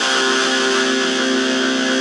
45 SYNTH 2-L.wav